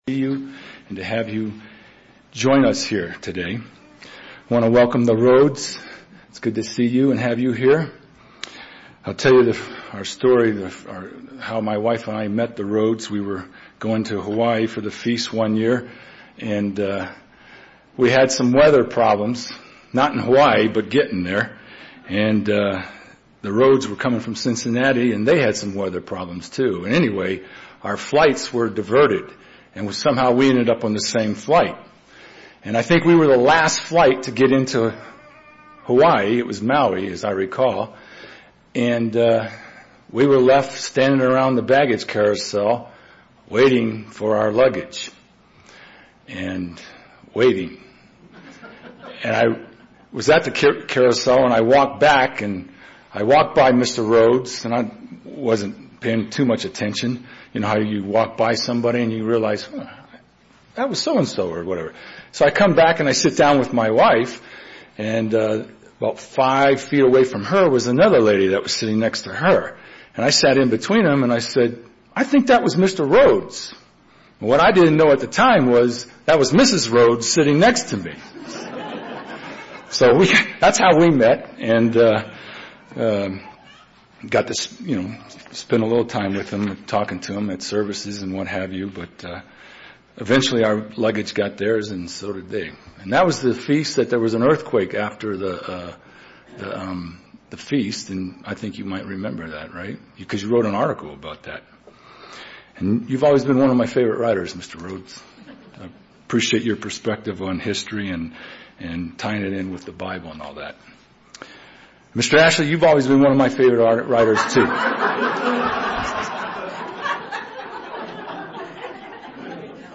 Given in Denver, CO
UCG Sermon Studying the bible?